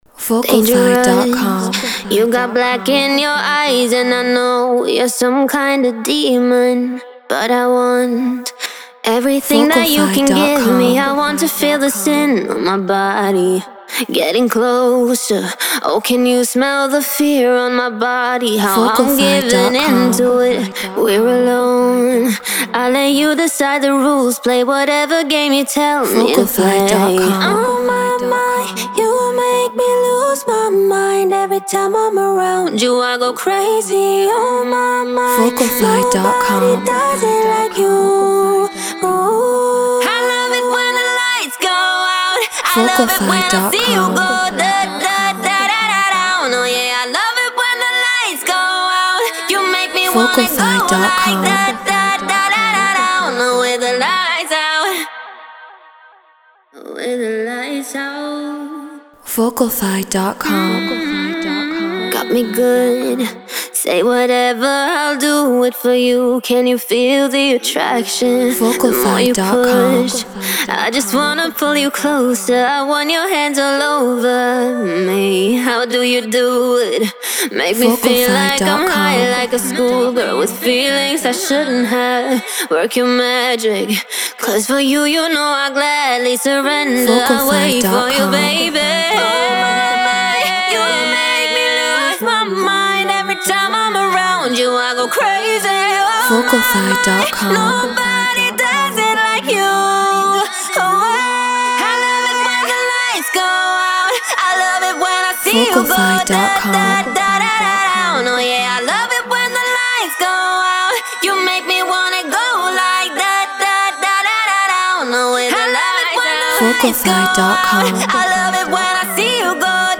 Get Royalty Free Vocals.